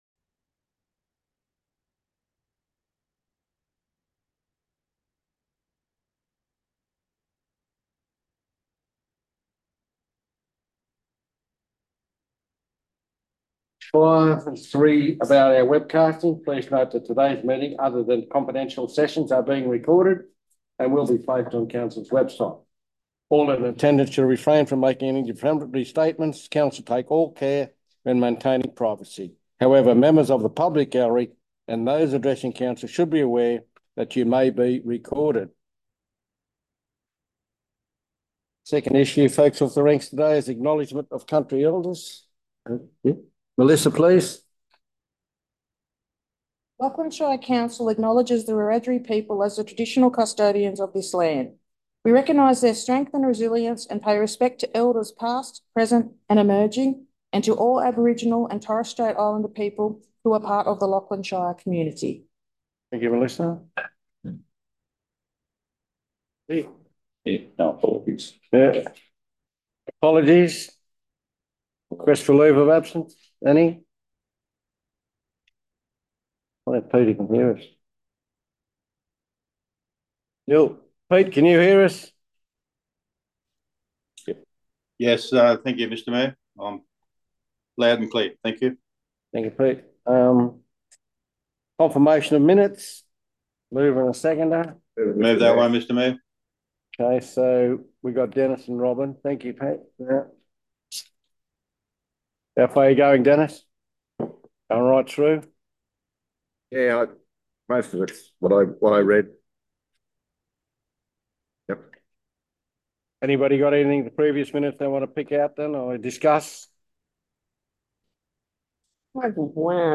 19 June 2024 Ordinary Meeting
The June 2024 meeting will be held in the Council Chambers at 2:00pm and is open to the public.